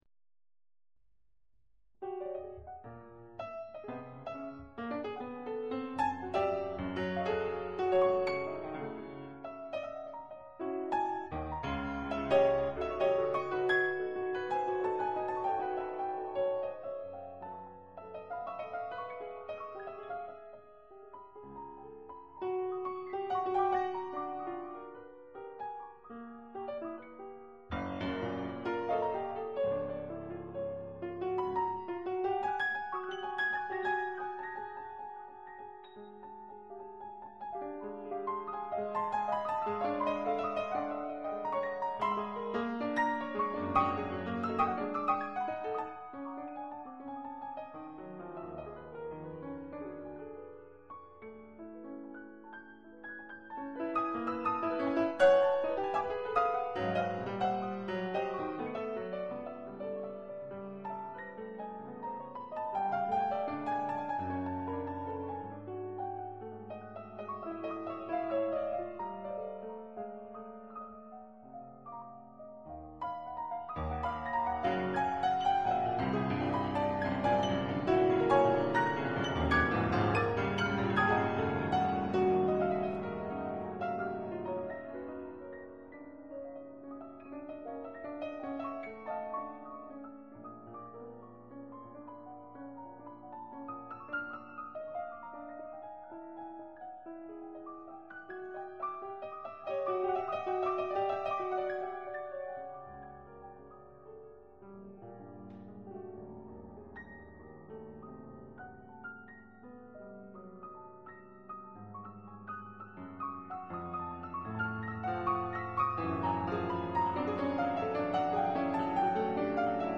* Secondo pianoforte